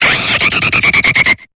Cri de Bruyverne dans Pokémon Épée et Bouclier.